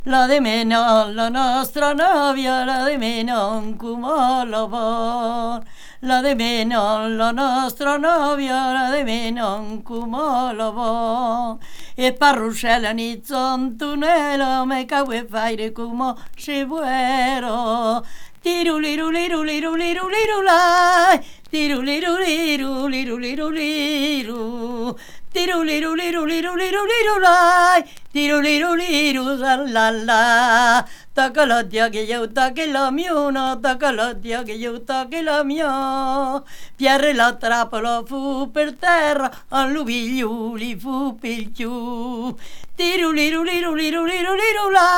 本盤ではフランスを題材に、南仏の伝承歌を収録。
Folk, World　France　12inchレコード　33rpm　Stereo